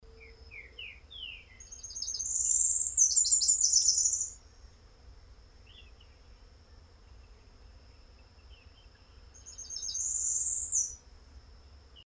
Tropical Parula (Setophaga pitiayumi)
Life Stage: Adult
Country: Argentina
Location or protected area: Reserva Privada y Ecolodge Surucuá
Condition: Wild
Certainty: Observed, Recorded vocal